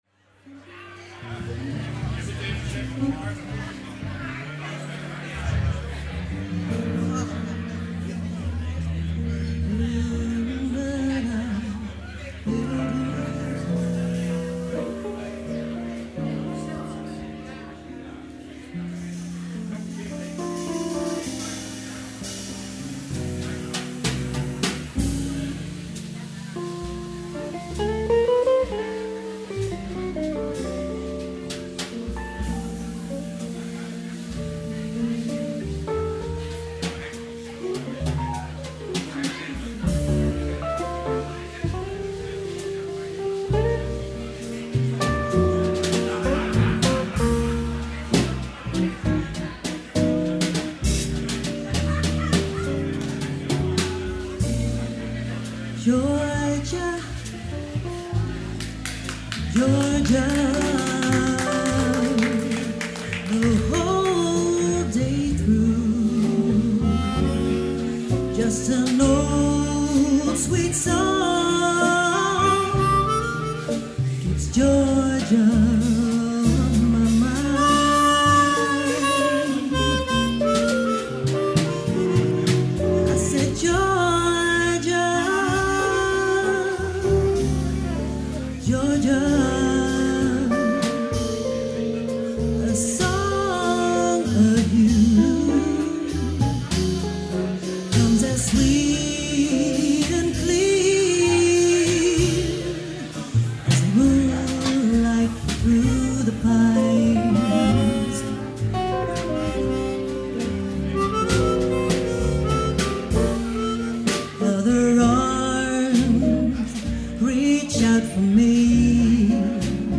Jazz
Zang
Mondharmonica
Tenorsax
Altsax
Gitaar
Piano
Drums